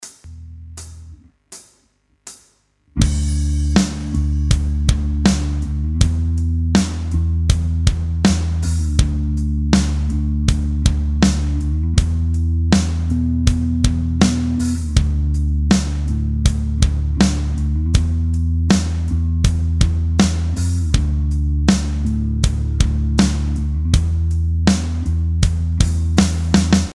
Backing-Track-4-2.mp3